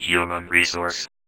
VVE1 Vocoder Phrases
VVE1 Vocoder Phrases 28.wav